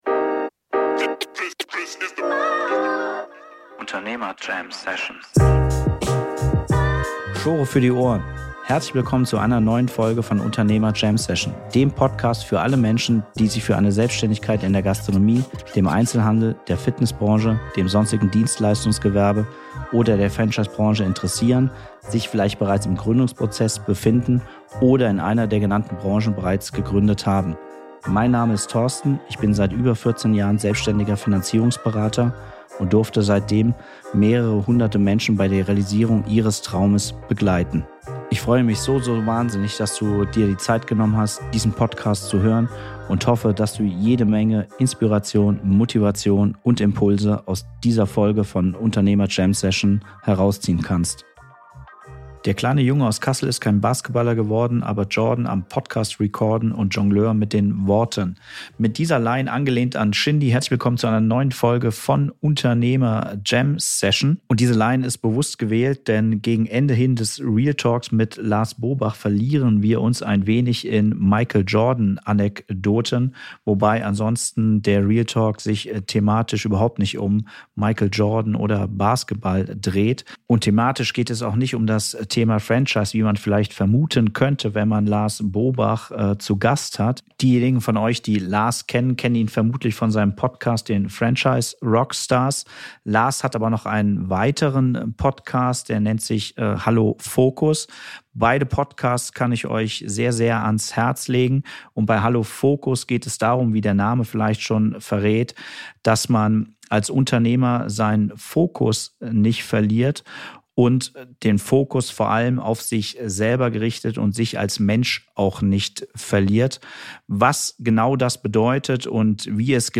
Ein inspirierendes Gespräch